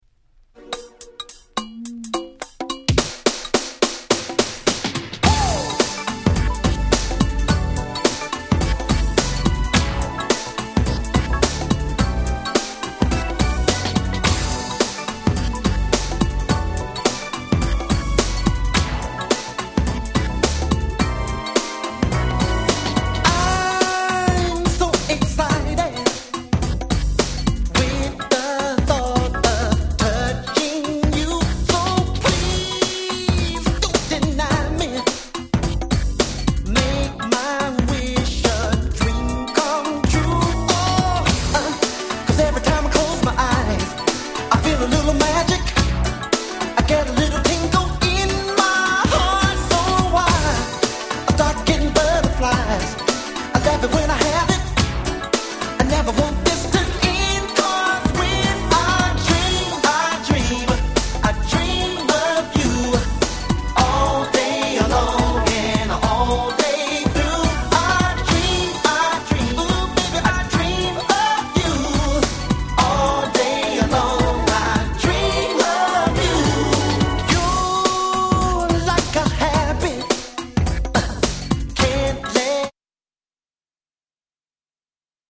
New Jack Swing Classic!!
Beatの強さをグンとUPさせたRemix、SmoothなAlbum Versionとどちらを使うか悩みます！！